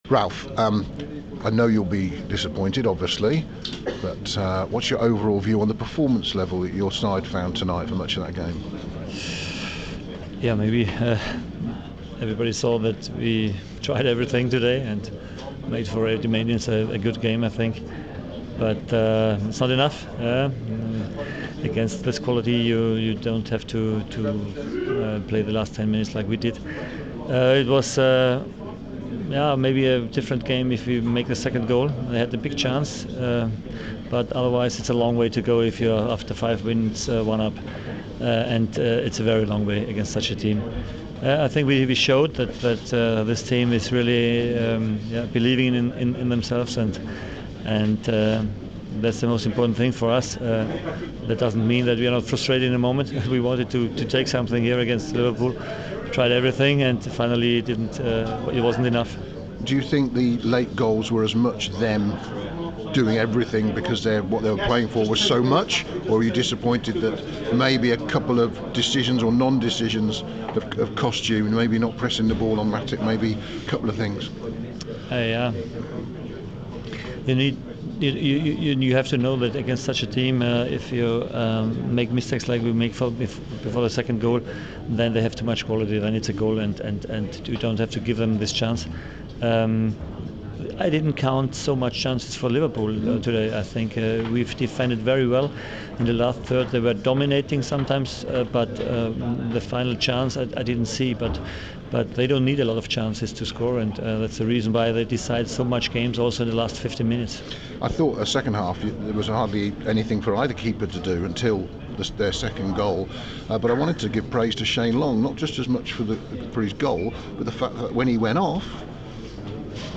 Saints boss Ralph Hasenhuttl speaking after the 3-1 defeat to Liverpool